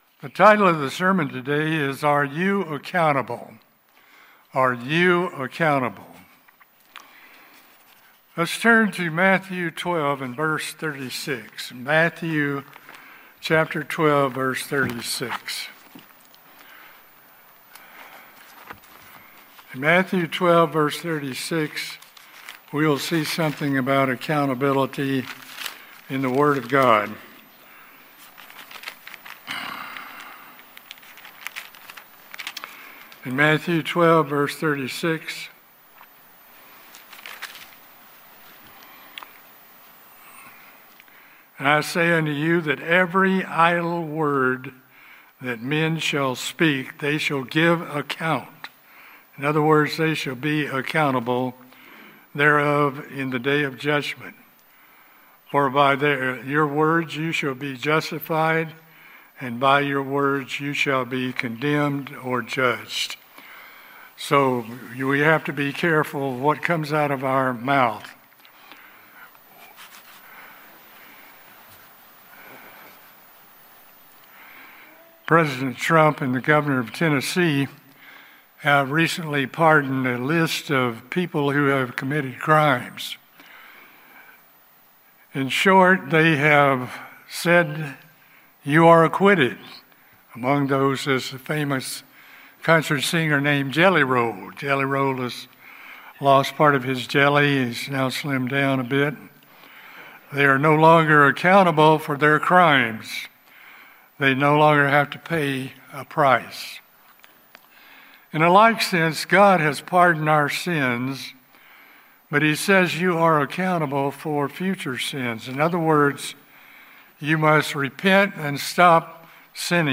We are responsible and accountable for preserving these precious truths. This sermon identifies many of these truths and urges believers to remain faithful to our calling and discern the times in which we are living.